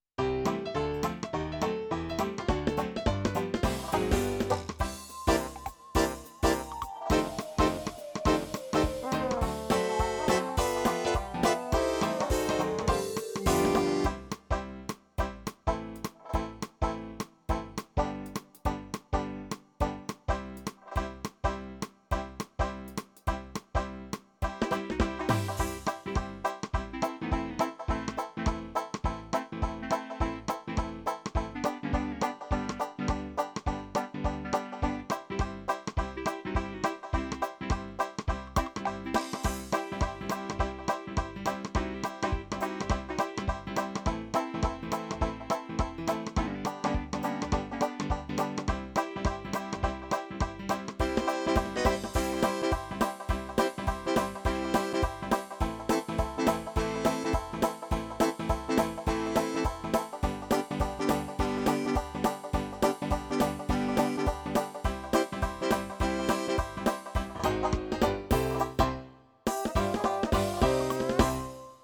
2 introducing 4 Main variations 4 fills break and 2 ending.